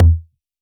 RDM_Copicat_SR88-Kick.wav